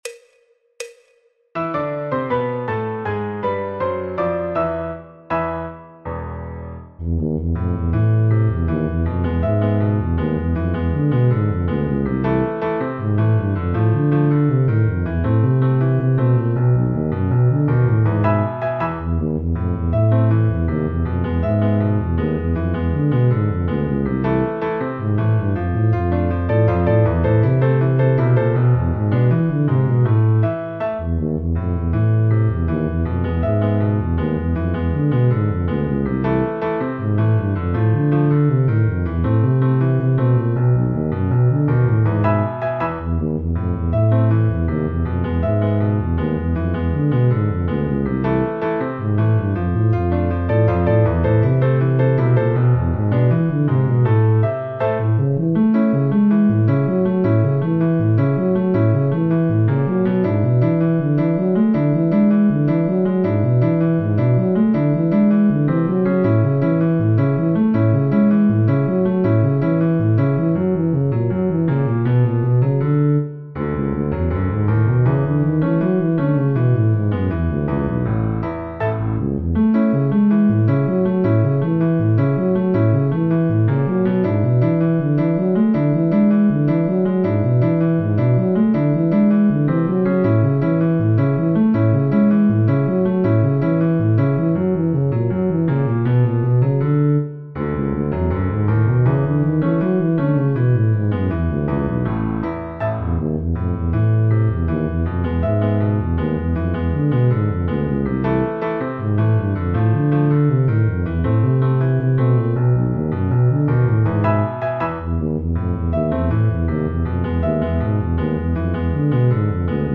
en La menor : La Mayor
Choro, Jazz, Popular/Tradicional
Contrabajo, Tuba